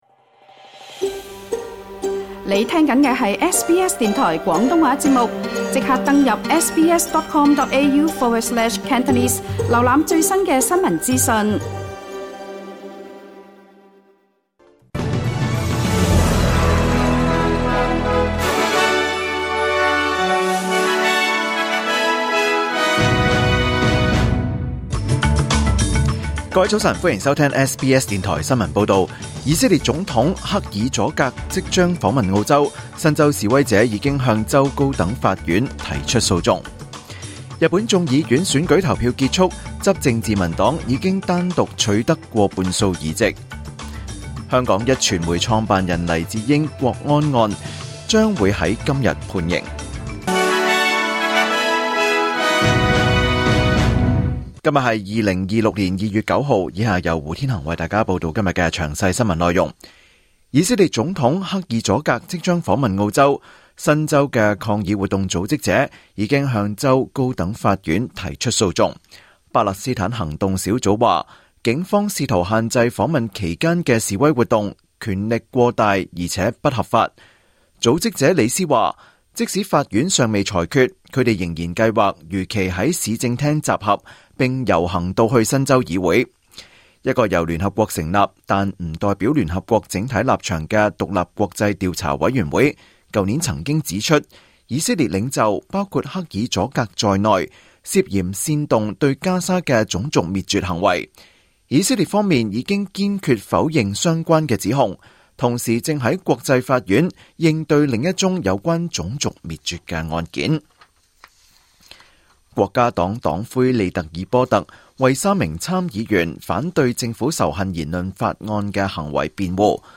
2026 年 2 月 9 日SBS廣東話節目九點半新聞報道。